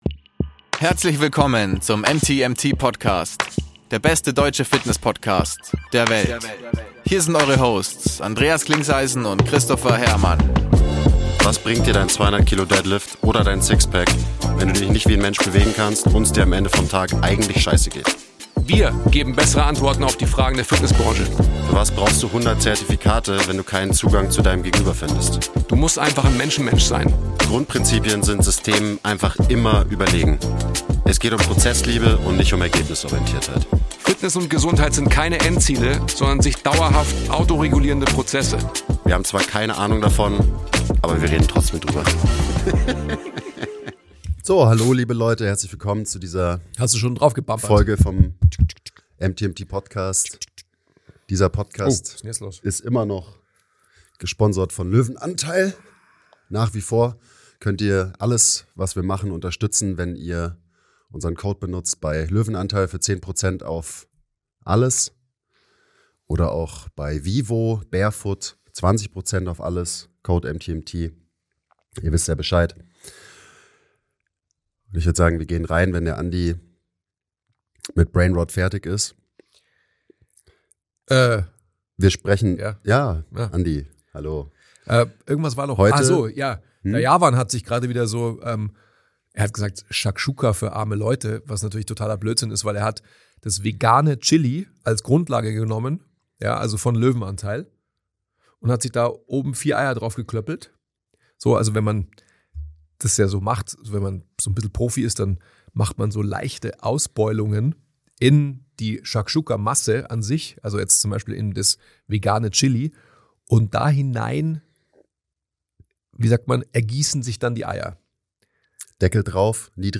Die beiden schauen nämlich in die Vergangenheit und lesen euch aus ihren alten Trainings Büchern vor.